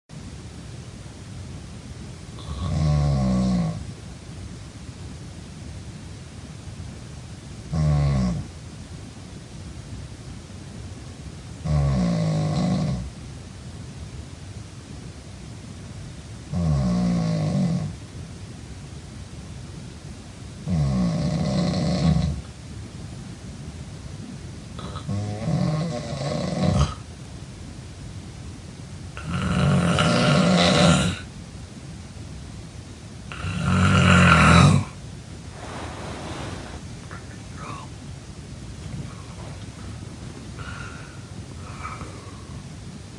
Snoring 2